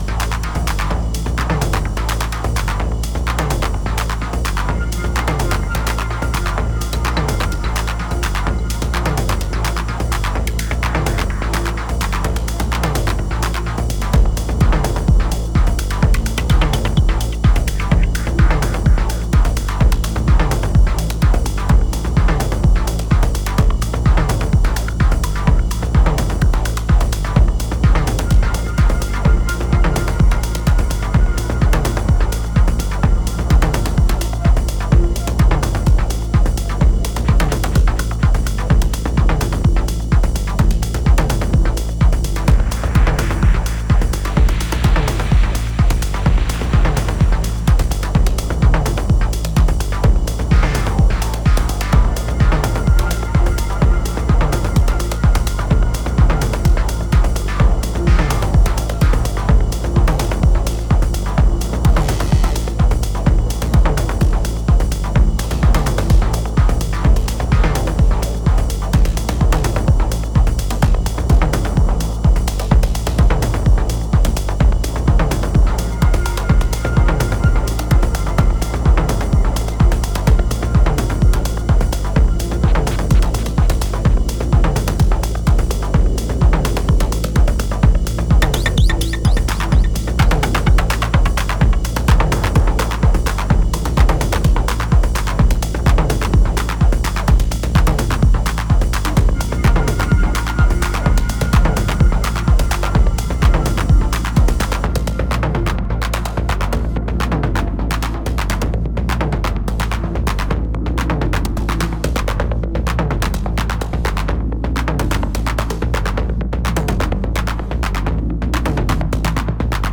催眠性の高いアシッド・テクノを展開しています